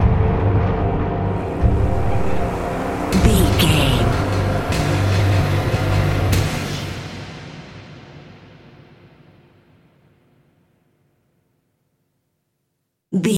Thriller
Atonal
synthesiser
percussion